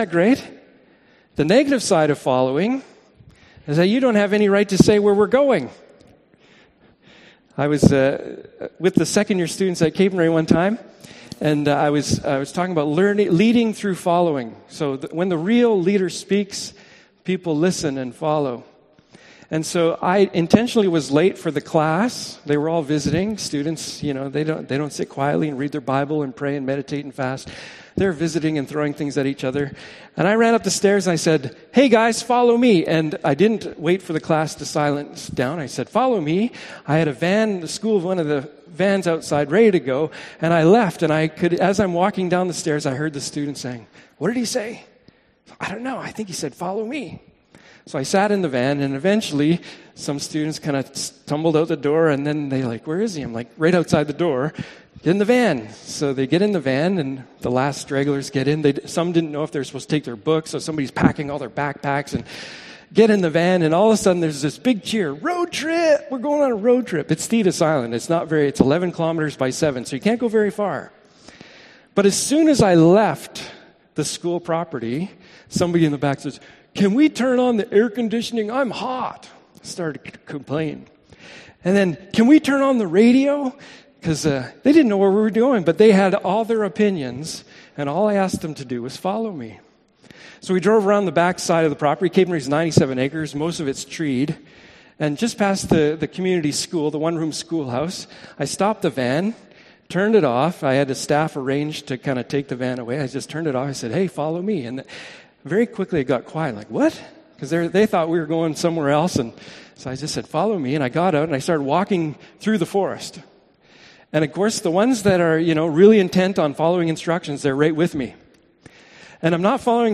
Ephesians 4:11-6 Service Type: Morning Service « Acts of Jesus